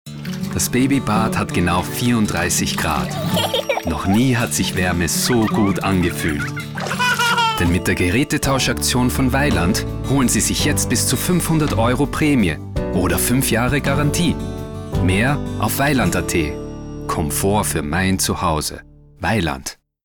My voice "flows like water" - it's soothing, calm, but can be present and powerful.
Sprechprobe: Werbung (Muttersprache):